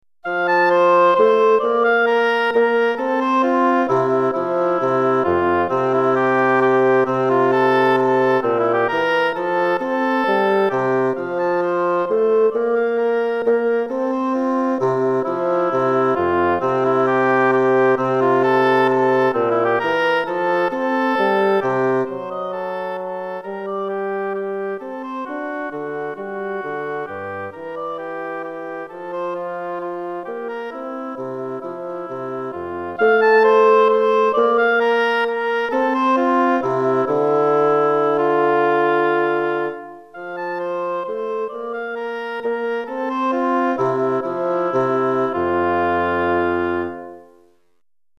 Hautbois et Basson